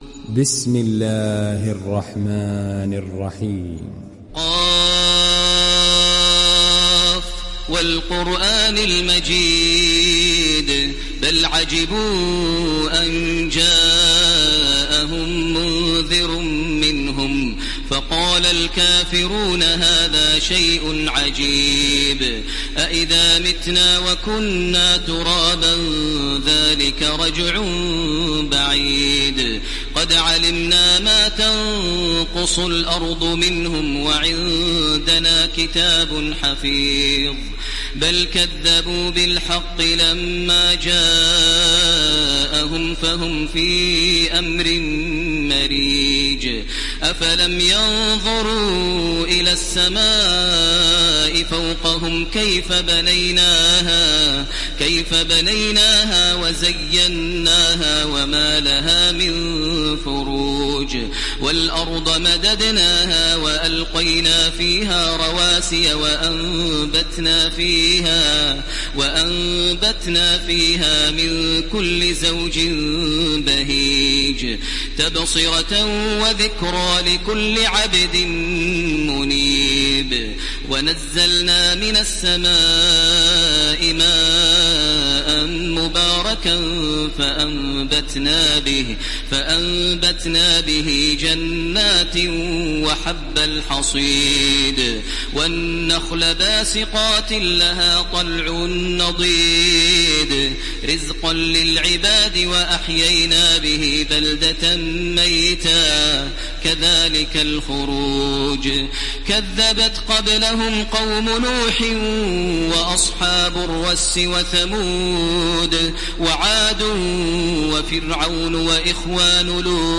ডাউনলোড সূরা ক্বাফ Taraweeh Makkah 1430